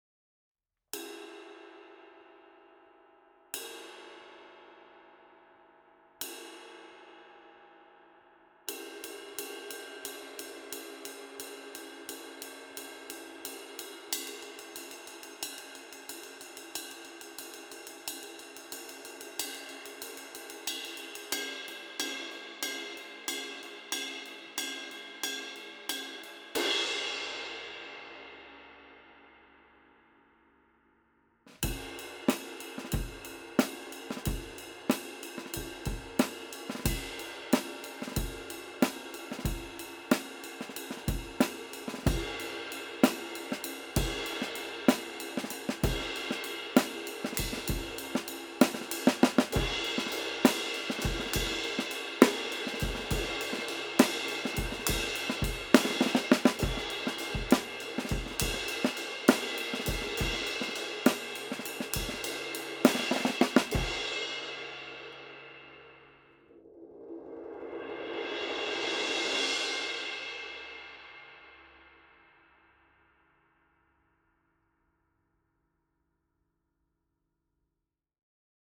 Hand-hammered from B20 bronze.